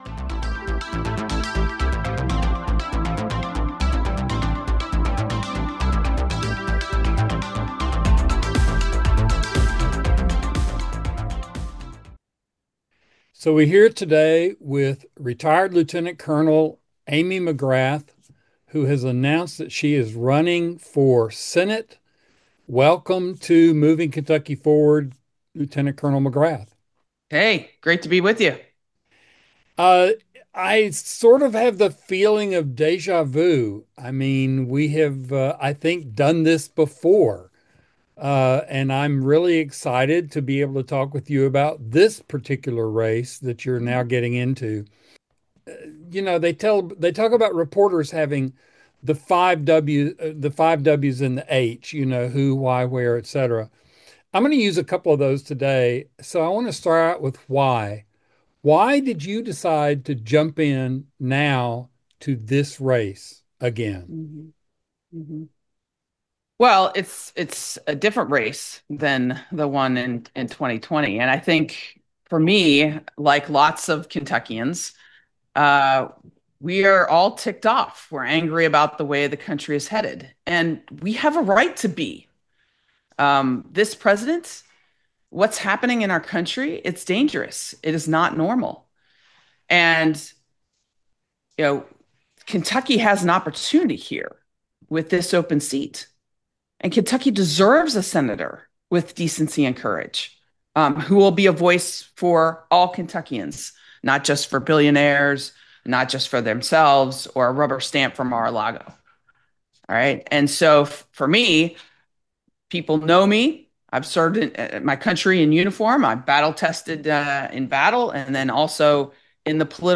Moving Kentucky Forward An interview with Amy McGrath